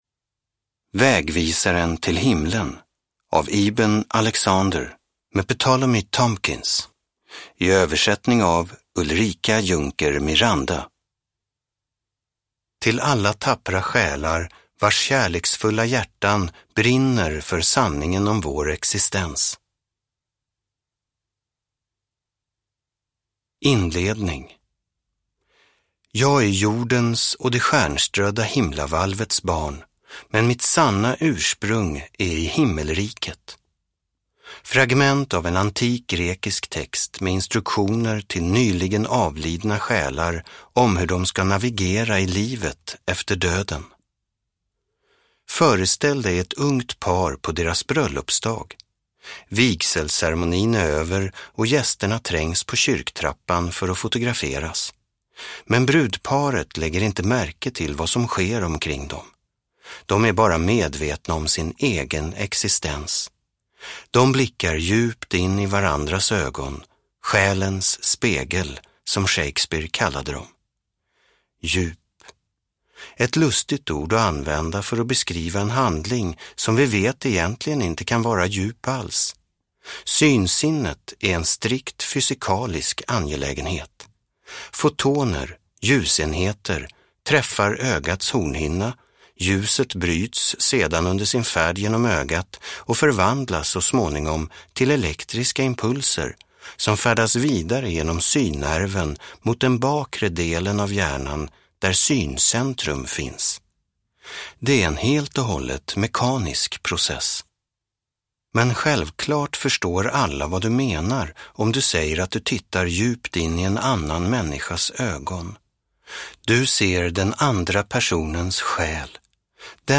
Vägvisaren till himlen : hur vetenskap, religion och vanliga människor påvisar att det finns ett liv efter döden – Ljudbok – Laddas ner